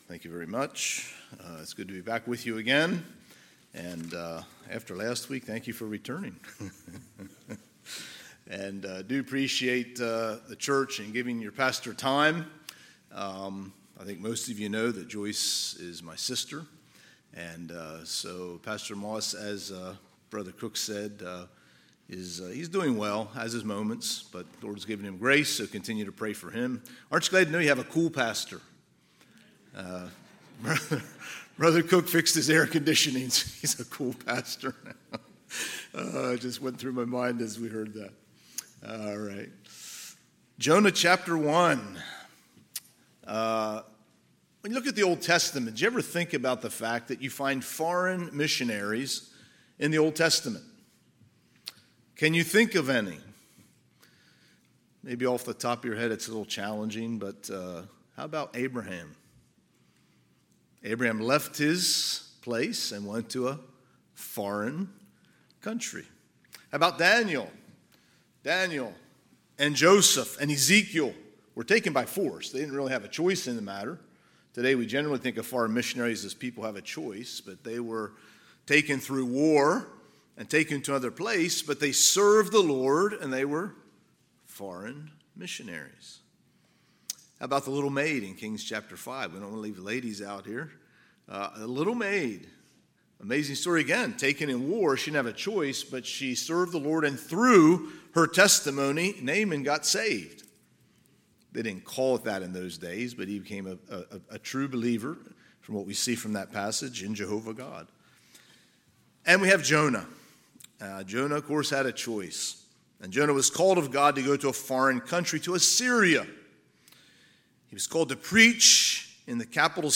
Sunday, September 3, 2023 – Sunday AM